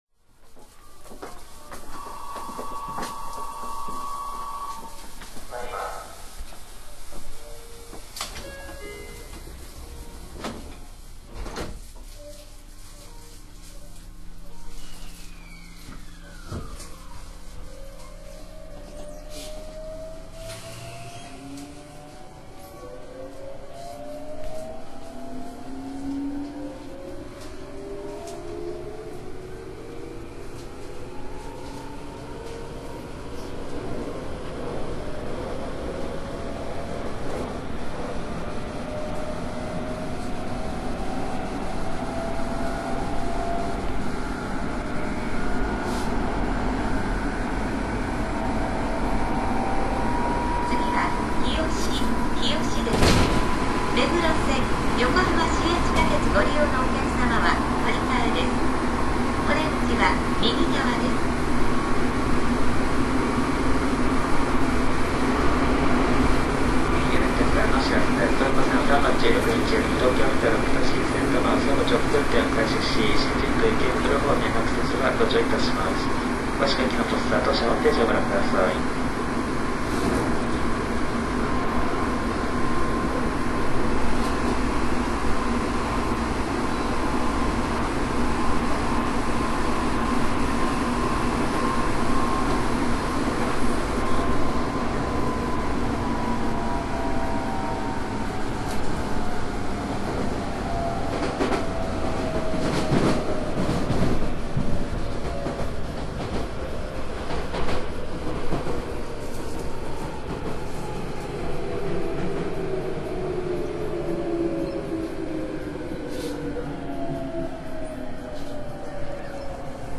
東洋電機製の後期ＧＴＯ−ＶＶＶＦインバータを装備します。
ＰＡＲＴ２　綱島〜日吉 （１．０５ＭＢ）